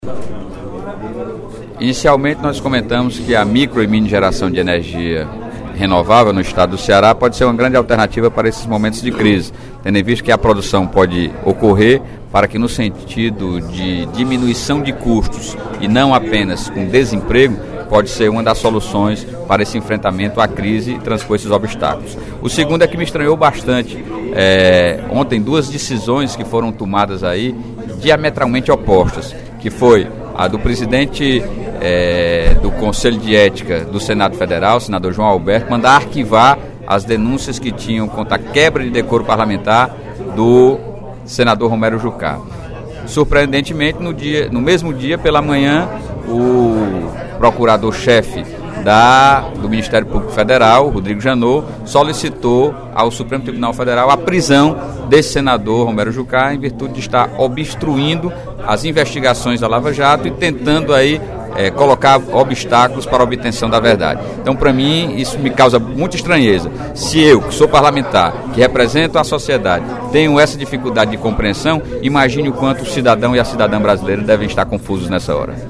O deputado Sérgio Aguiar (PDT) defendeu, no primeiro expediente da sessão plenária desta quarta-feira (08/06), a utilização de energia renovável pelas indústrias cearenses.